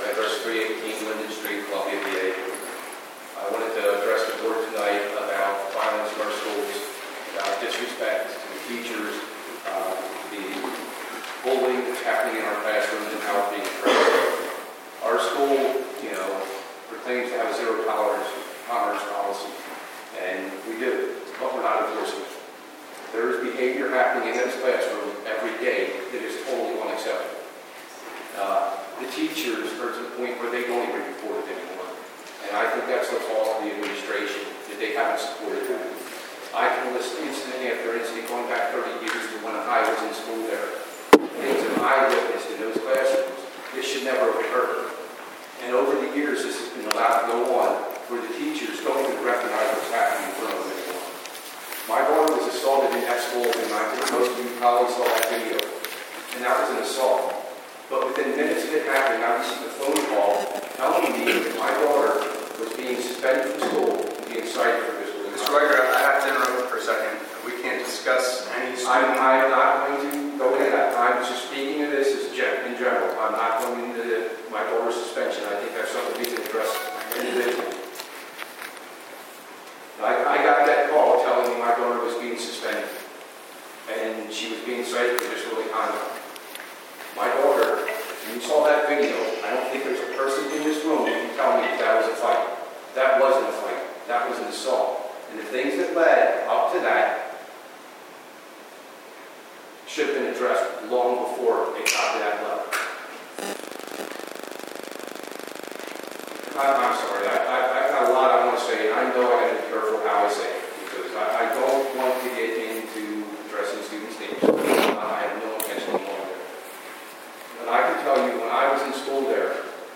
Citizens, residents showed up and spoke up at last night’s school board meeting